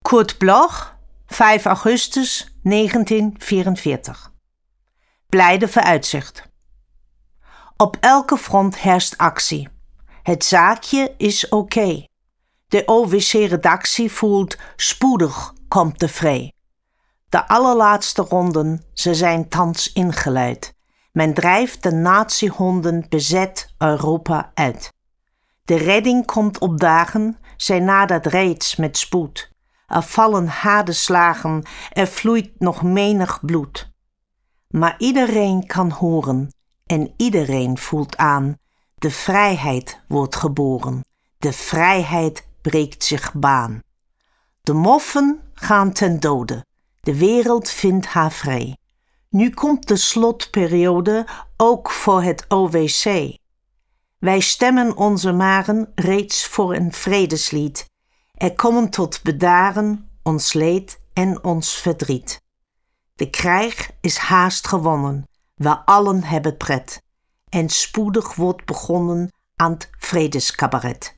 Recording: Residenztheater München · Editing: Kristen & Schmidt, Wiesbaden